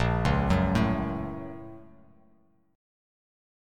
BbmM7#5 chord